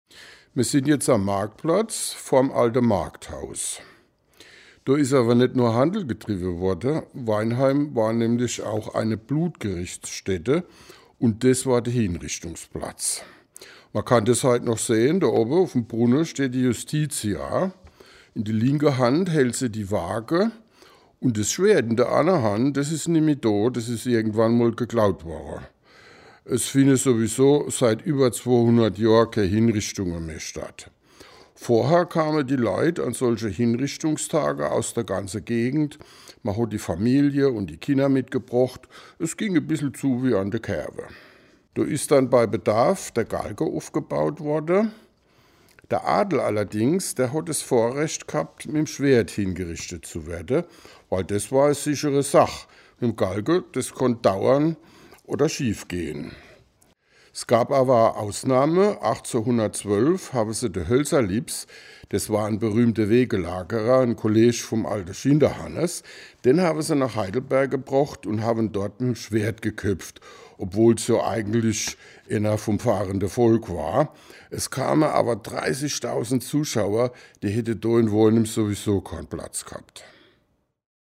Mit Mundart unterwegs in der Altstadt
An 9 Stationen in der Innenstadt können Besucher über einen QR-Code mit dem Smartphone Anekdoten in Mundart anhören.